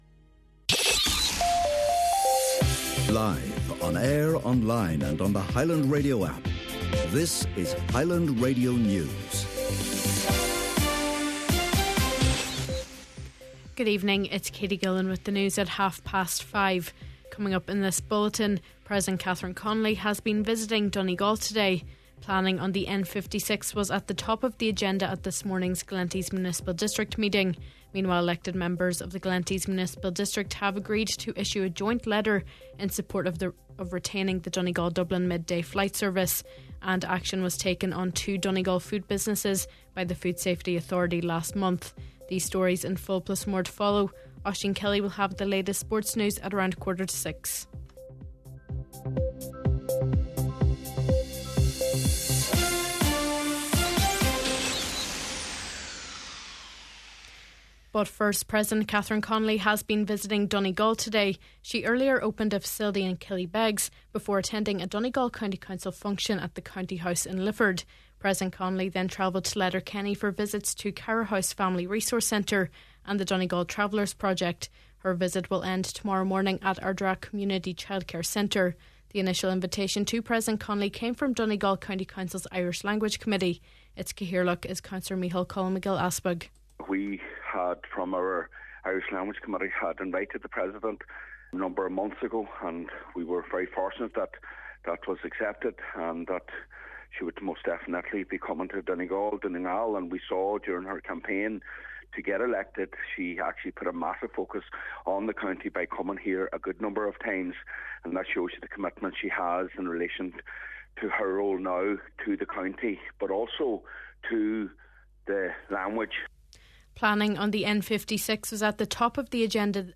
Main Evening News, Sport and Obituary Notices – Tuesday March 10th